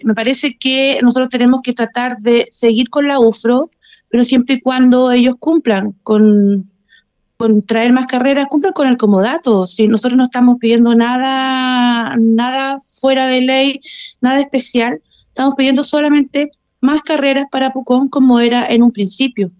Mientras que la edil del Frente Amplio, Verónica Castillo, dijo que la casa de estudios tiene que cumplir con las obligaciones suscritas hace más de 20 años.